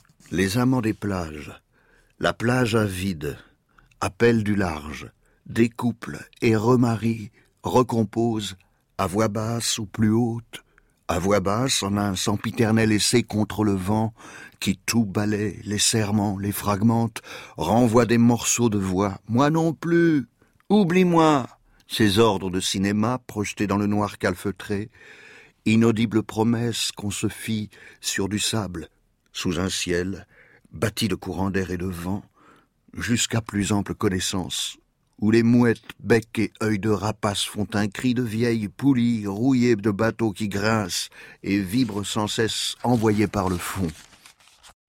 (Archive) Jacques Bonnaffé lit des extraits de "Ciné-Plage" d'Etienne Faure
Dans l’émission de Jacques Bonnaffé, sur France Culture, ce jour-là, (13 avril 2016) plusieurs extraits de Ciné-Plage d’Etienne Faure, livre à propos duquel Poezibao vient de publier une note de lecture.
Ecouter Jacques Bonnaffé lire un extrait de Ciné-Plage d'Etienne Faure (44'')